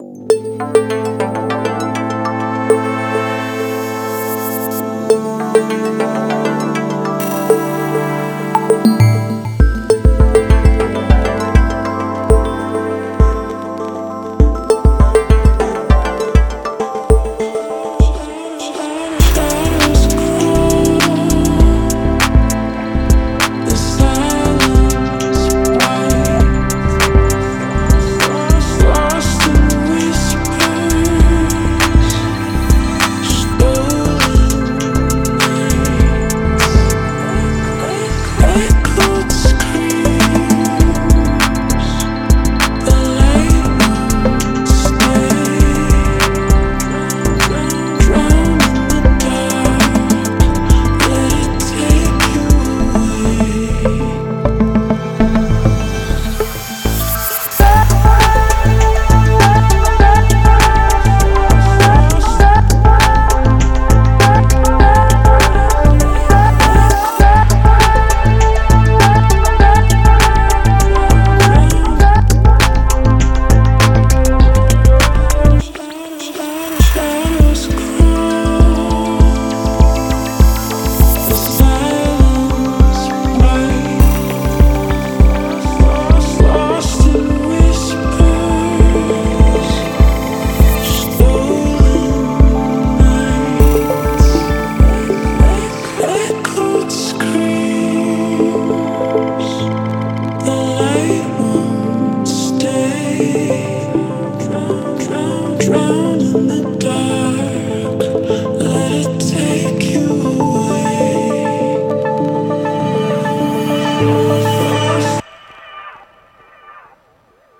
ダウンテンポ系では特に使い勝手も良く、
Genre:Downtempo
デモサウンドはコチラ↓
36 Synth Loops
9 Vocal Loops